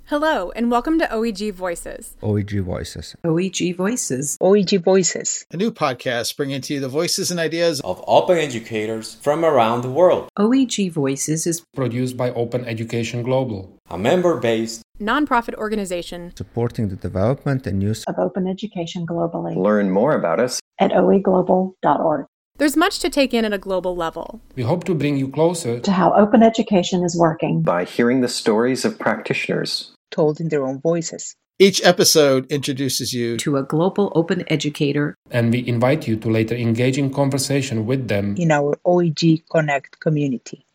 The version used up til now was made by having all of my colleagues at OEGlobal record a two paragraph bit of copy which I manually edited together, and the one track has been used in the first 26 episodes.